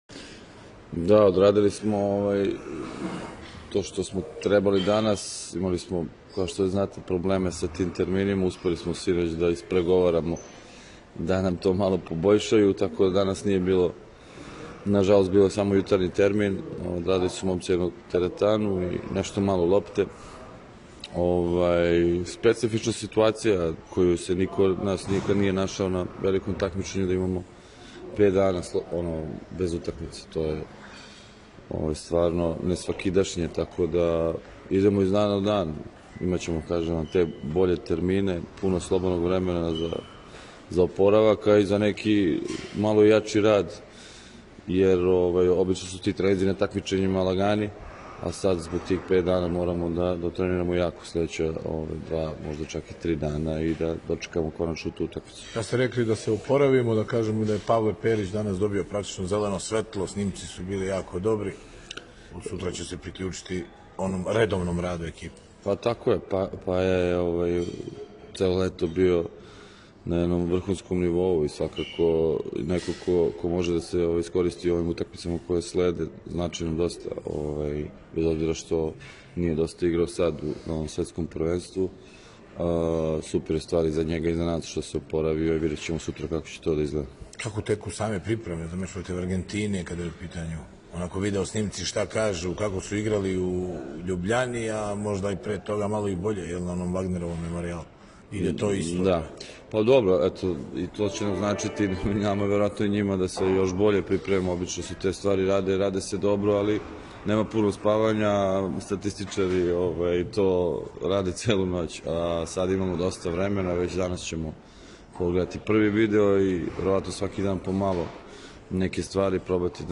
Izjava Bojana Janića